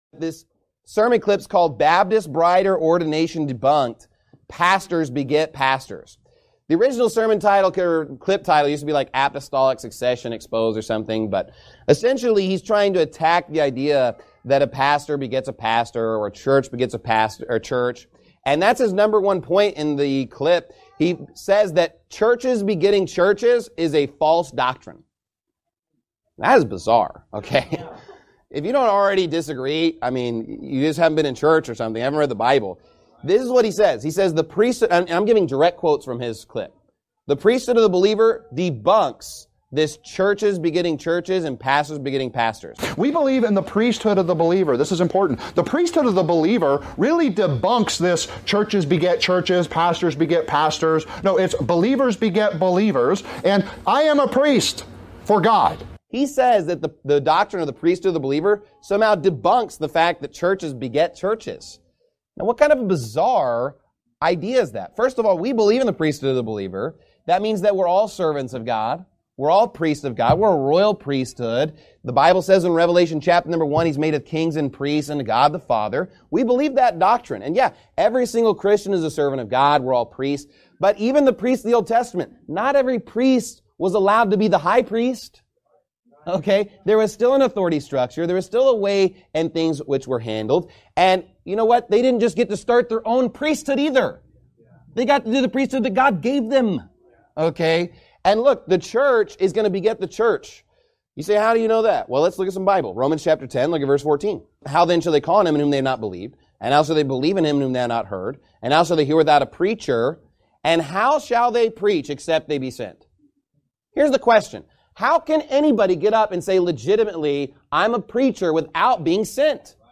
Every NIFB Sermon and More